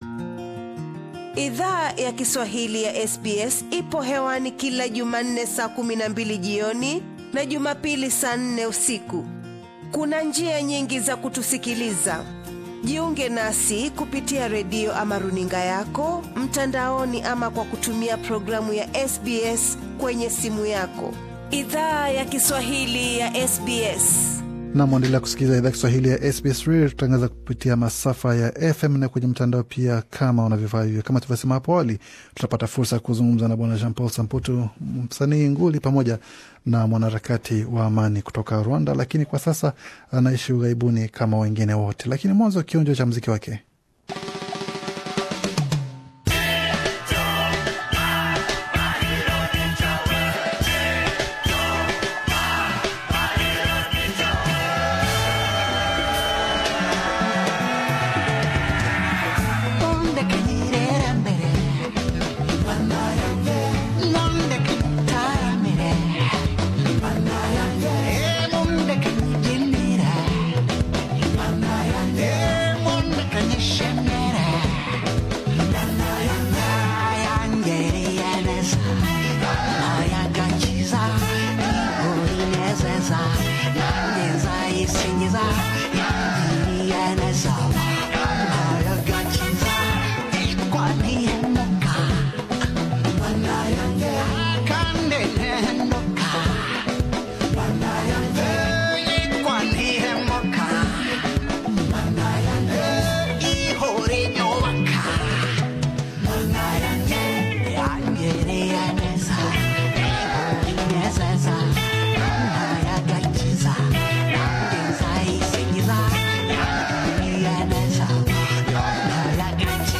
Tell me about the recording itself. akiwa ndani ya studio ya SBS Radio